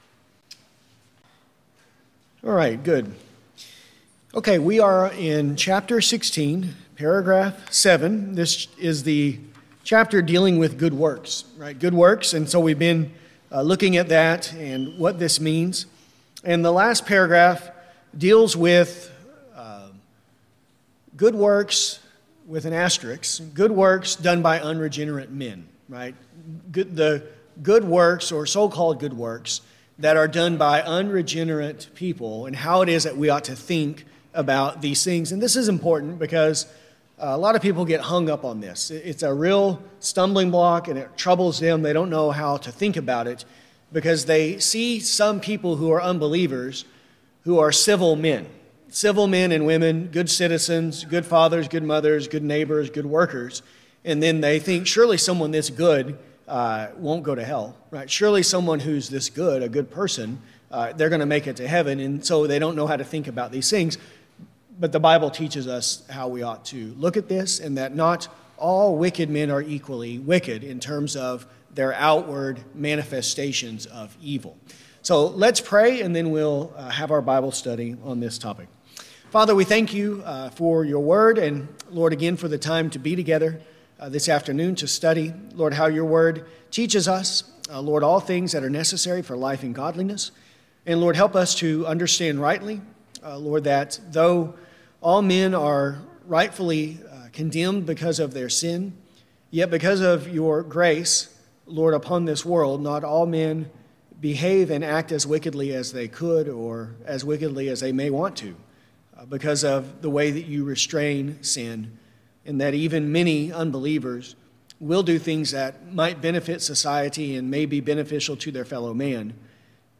Good works are only such as God hath commanded in his Holy Word, and not such as without the warrant thereof are devised by men out of blind zeal, or upon any pretense of good intentions. This lesson covers Paragraph 16.7. To follow along while listening, use the link below to view a copy of the confession.